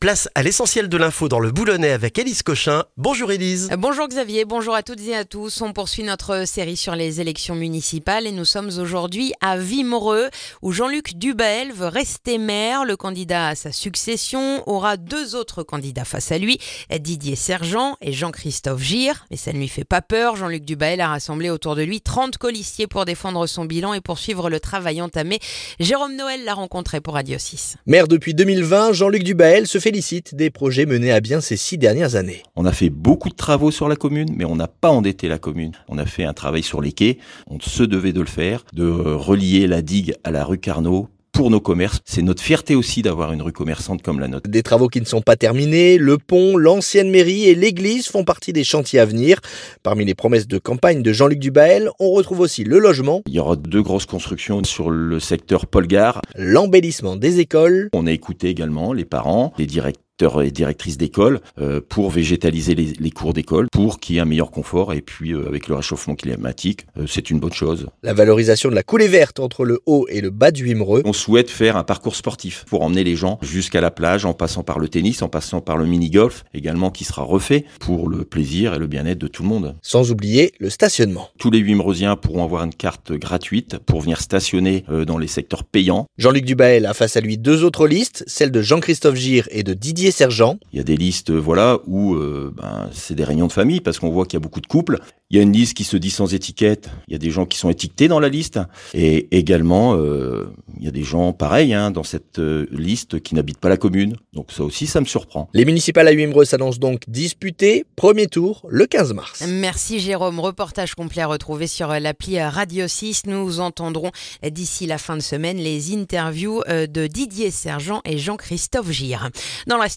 Le journal du mercredi 4 mars dans le boulonnais